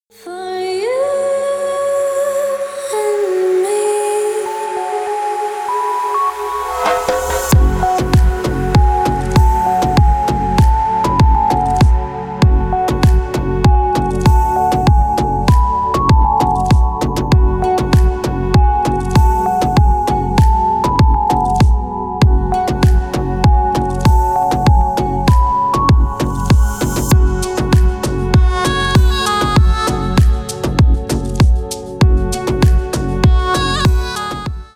Танцевальные
клубные # спокойные